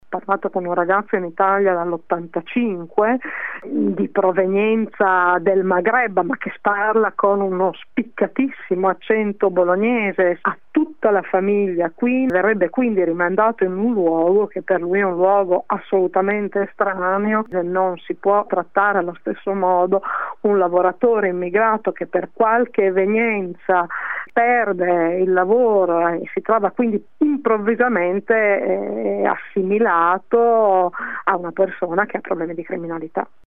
Ascolta la parlamentare Ghedini